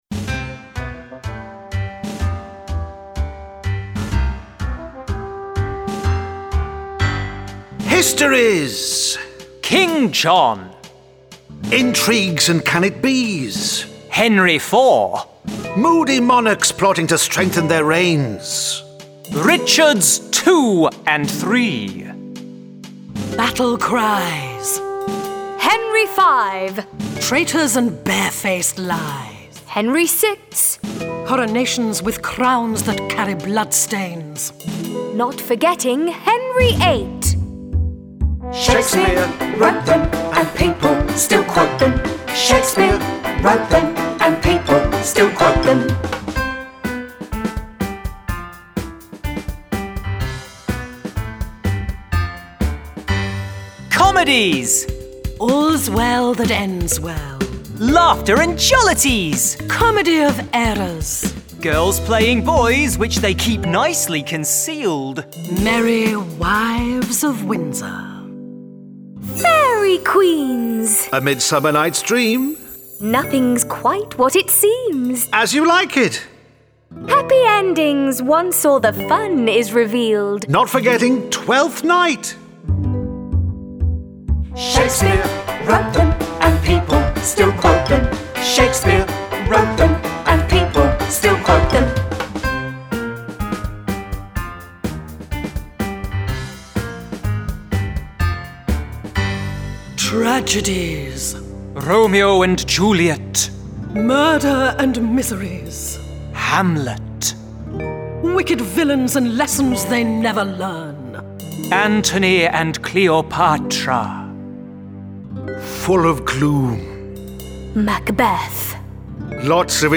Full vocal.